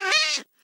sounds / mob / cat / hitt2.ogg